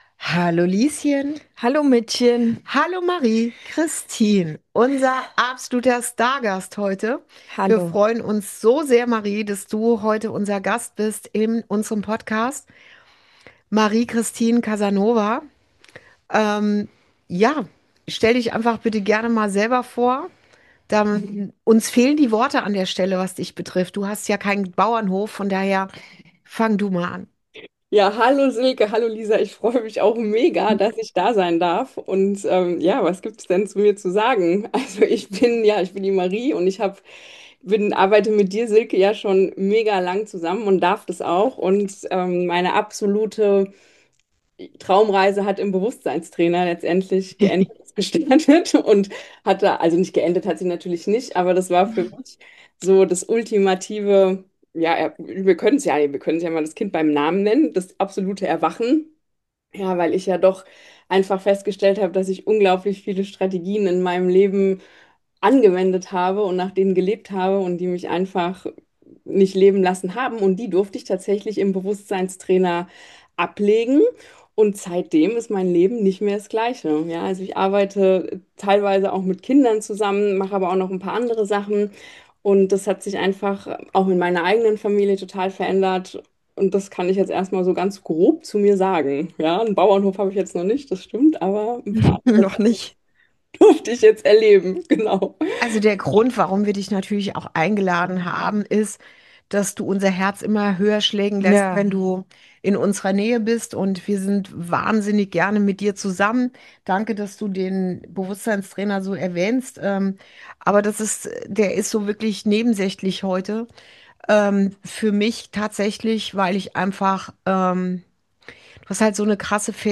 Ein Gespräch zwischen Mutter und Tochter Podcast